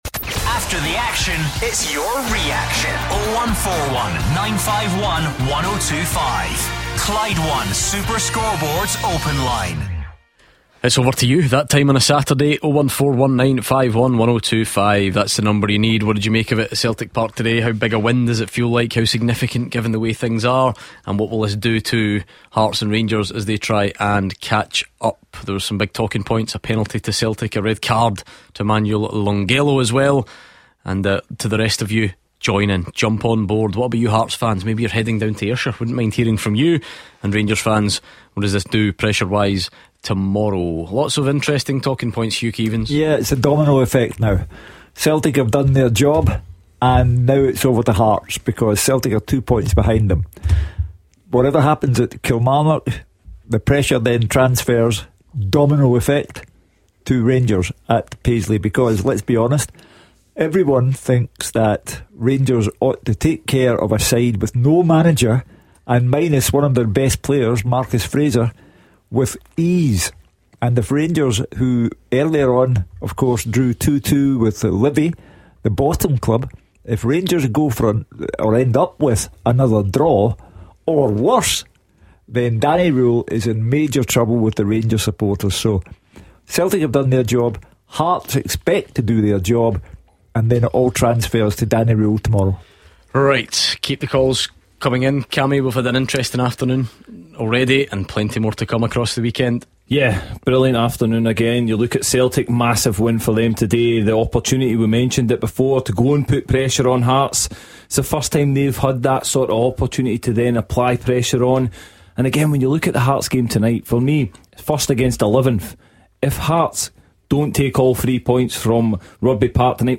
the lines open up after a small technical issue